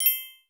Short Bell Alert.wav